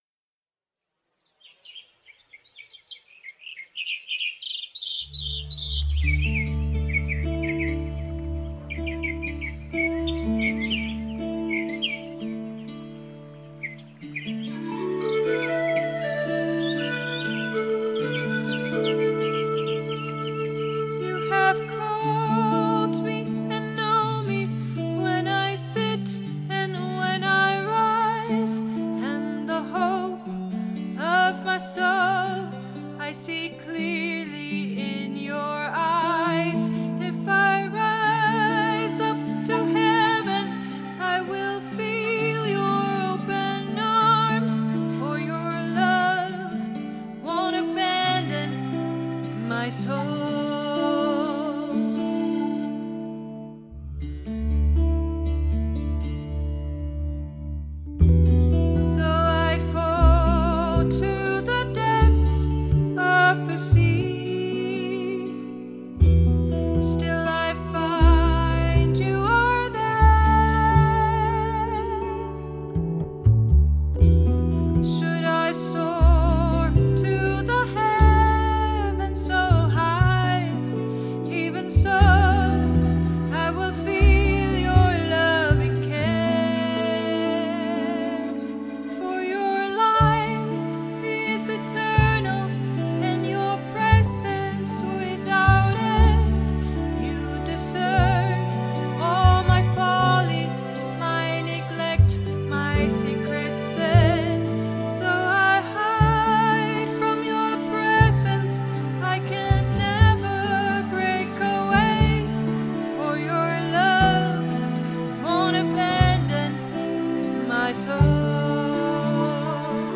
Vocal Recording